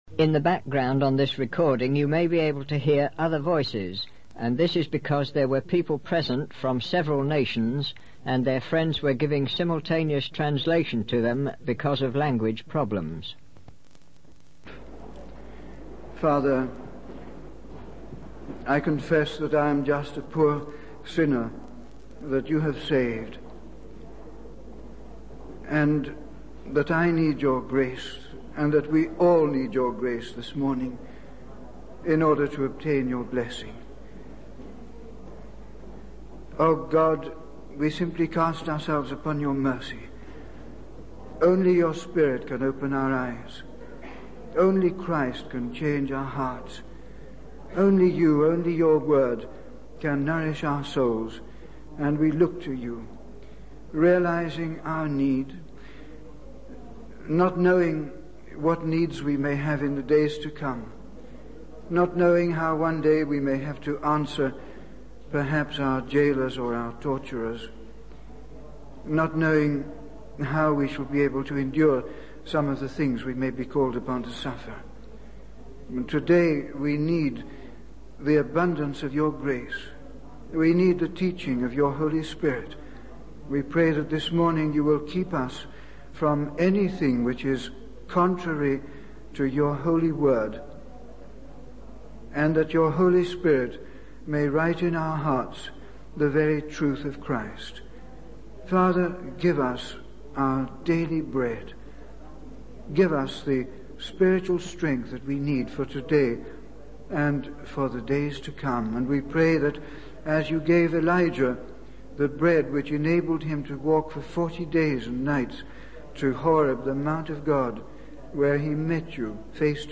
In this sermon, the speaker addresses the Corinthians, a church that has failed to mature spiritually.